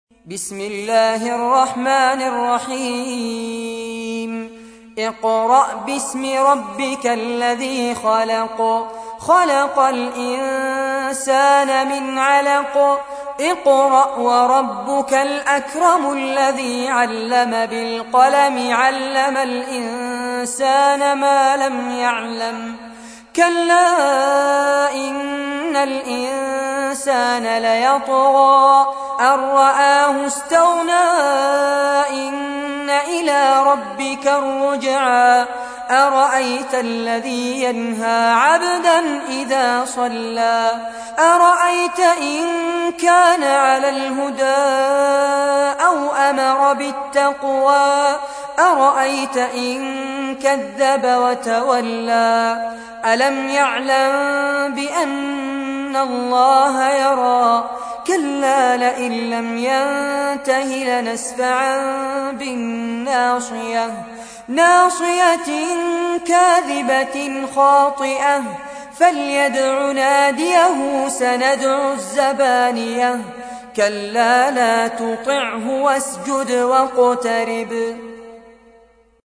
تحميل : 96. سورة العلق / القارئ فارس عباد / القرآن الكريم / موقع يا حسين